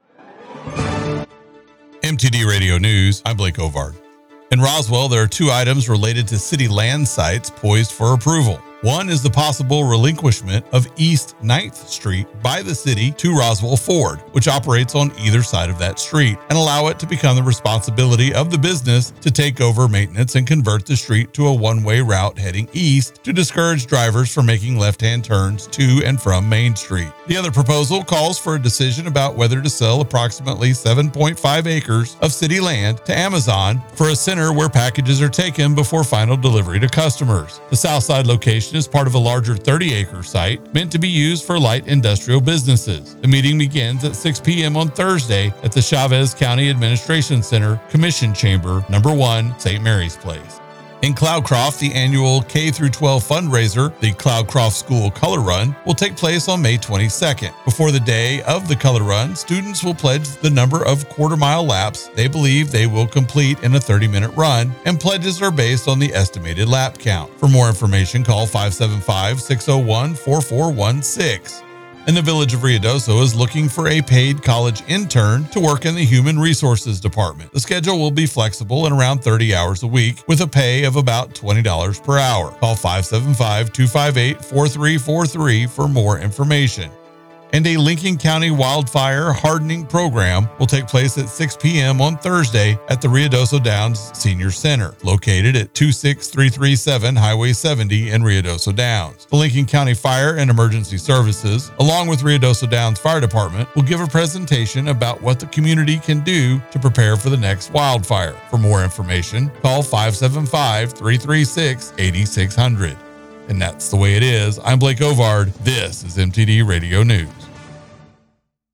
KWES News – Ruidoso and New Mexico